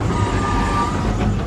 Oil Pump Moan Looped Long Beach Materials